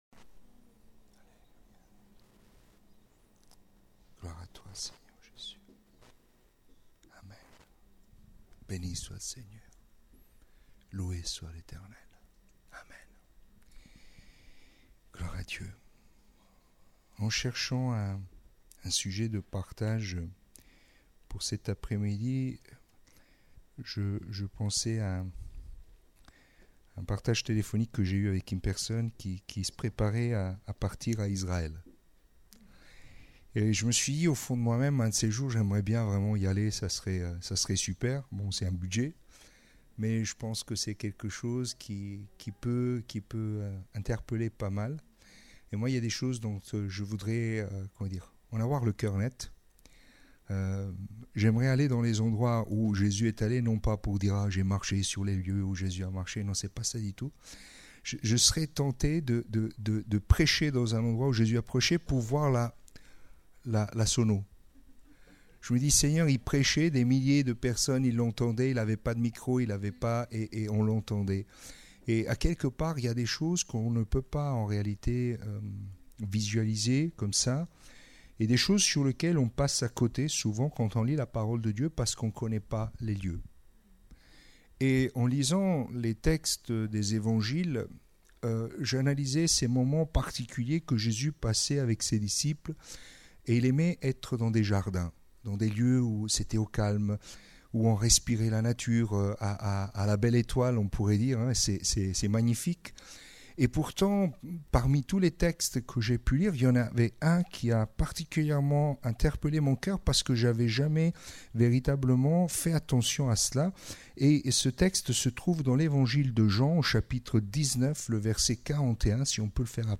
Etude